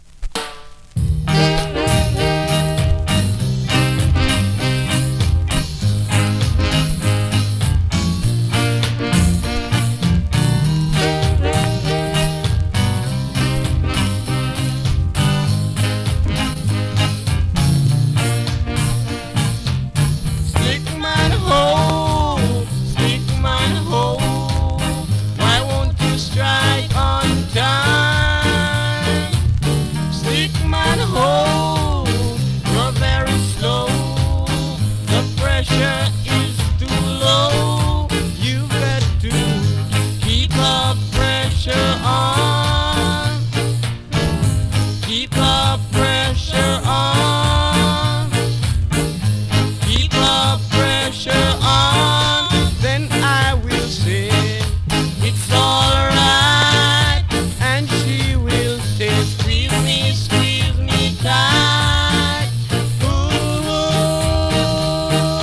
両面とも針圧重め推奨)   コメントレアSKA!!
スリキズ、ノイズ比較的少なめで